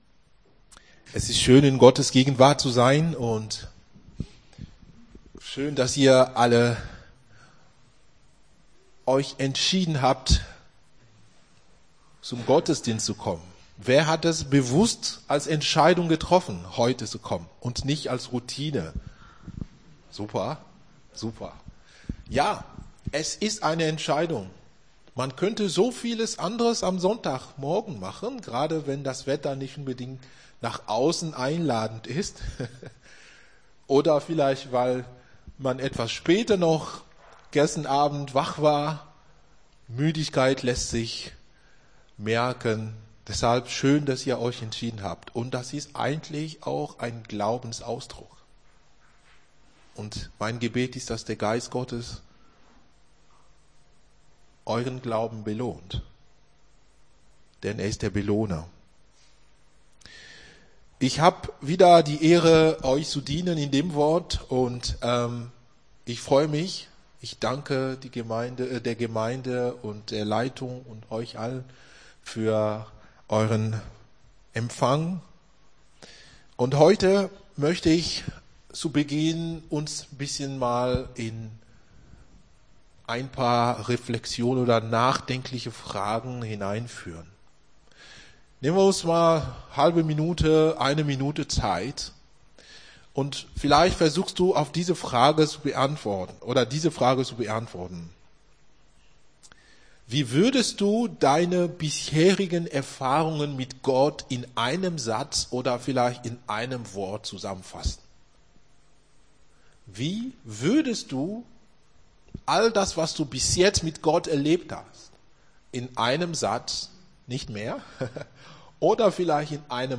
Gottesdienst 29.10.23 - FCG Hagen